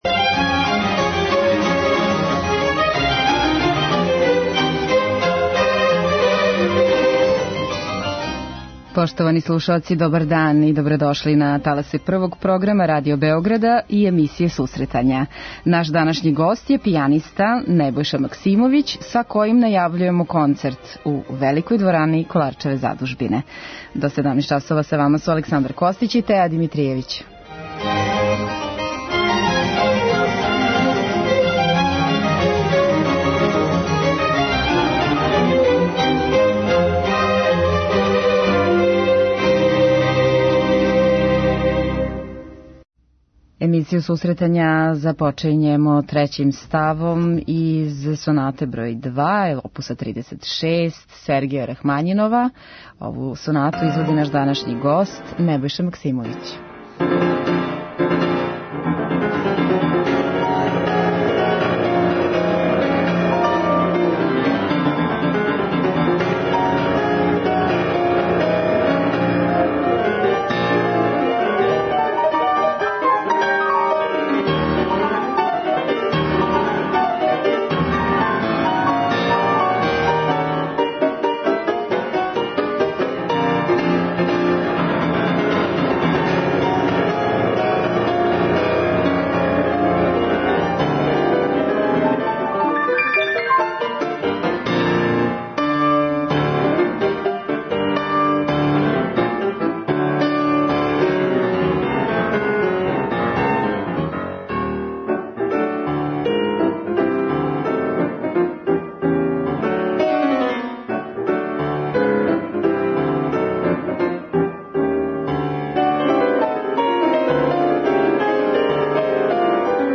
преузми : 10.79 MB Сусретања Autor: Музичка редакција Емисија за оне који воле уметничку музику.